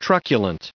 added pronounciation and merriam webster audio
1028_truculent.ogg